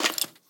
Minecraft Version Minecraft Version 1.21.5 Latest Release | Latest Snapshot 1.21.5 / assets / minecraft / sounds / mob / skeleton / step2.ogg Compare With Compare With Latest Release | Latest Snapshot
step2.ogg